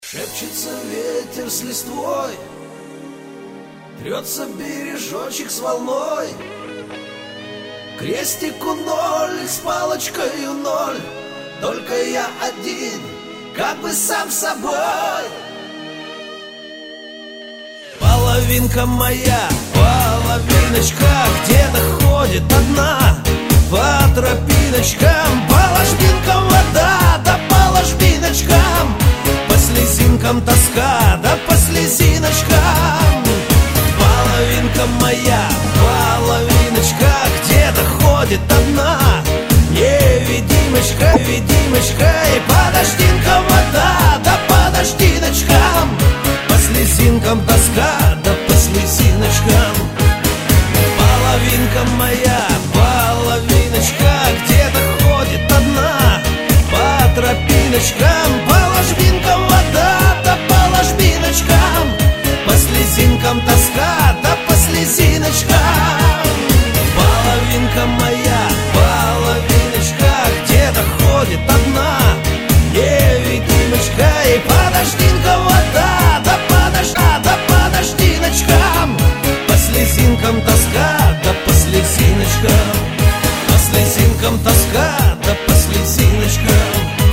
из Шансон
Категория - шансон.